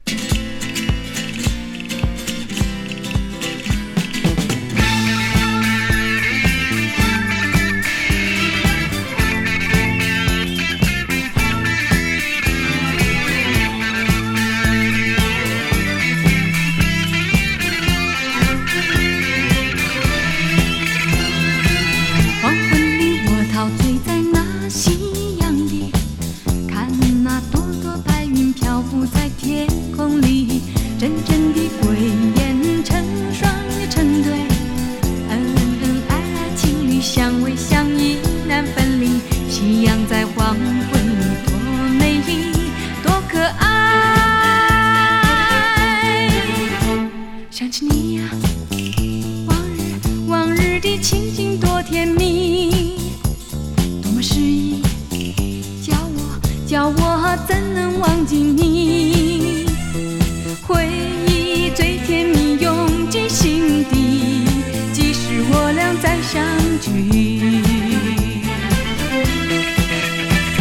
ポップス～ディスコ・アレンジ曲多数で、今の時代にもぴったりフィットしそうなサウンドです。